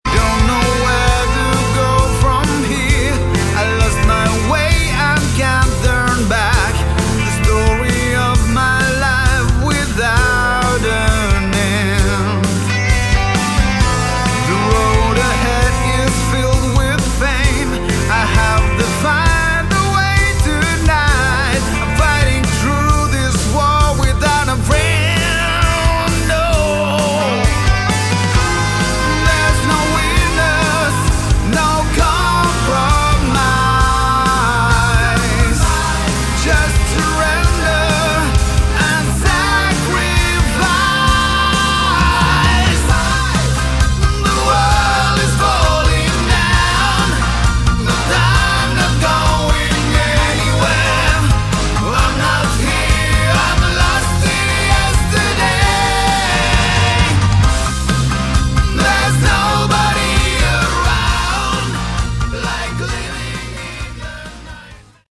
Category: Melodic Rock
drums, vst-bass, keyboards and backing vocals
lead and backing vocals
guitars